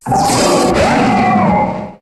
Cri de Fulguris dans sa forme Totémique dans Pokémon HOME.
Cri_0642_Totémique_HOME.ogg